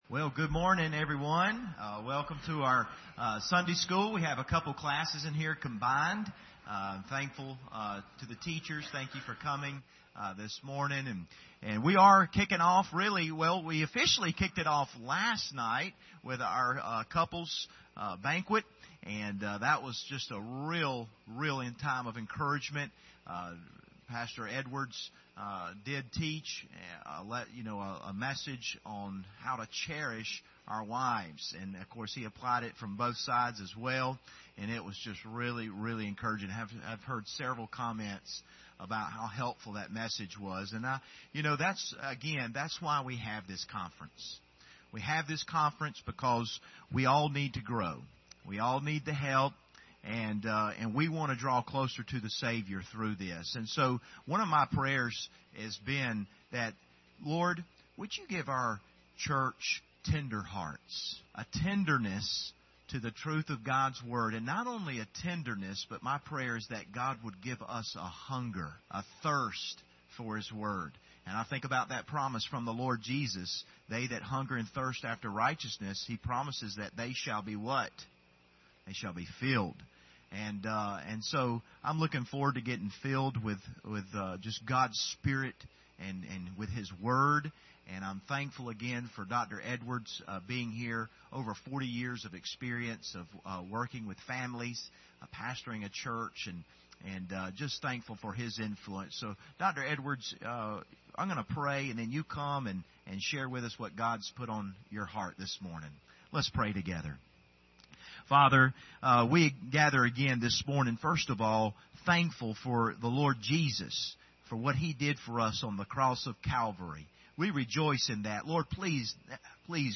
Series: 2019 Family Conference
Service Type: Sunday School Hour